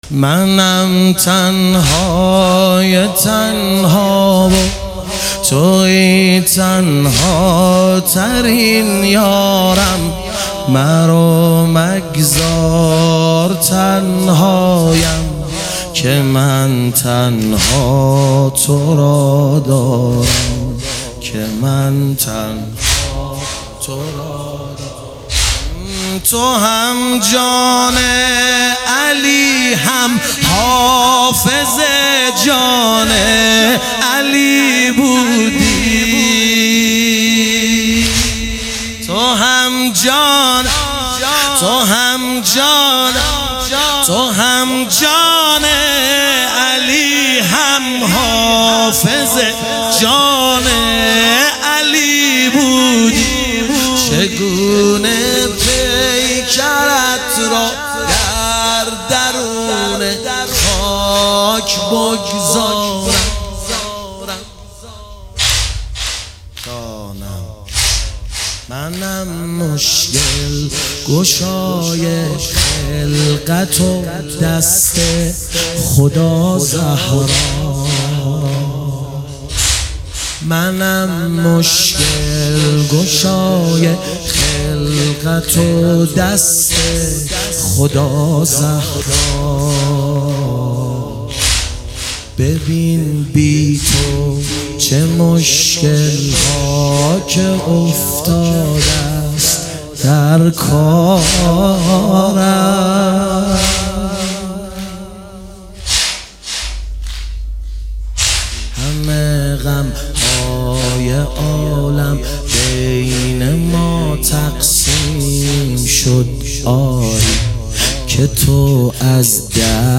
ایام فاطمیه 1399